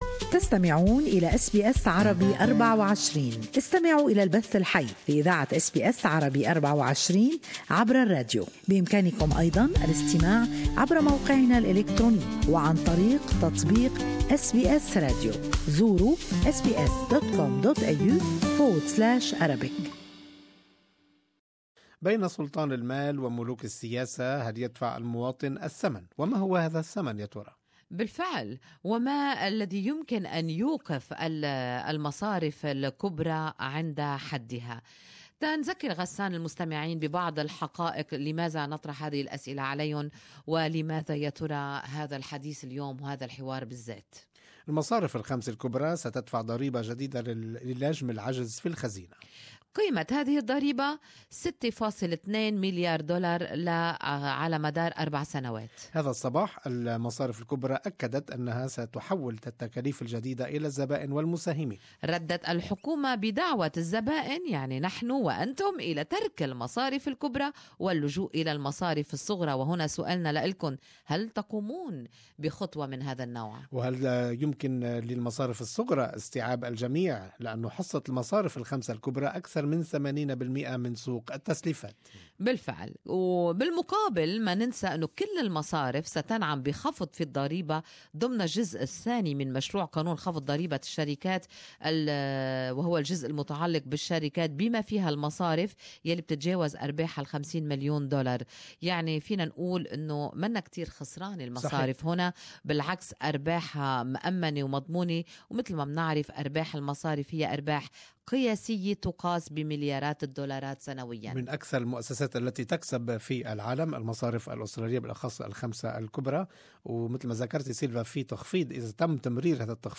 Good Morning Australia listeners share their opinions.